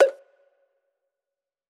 PERC - UPTOWN.wav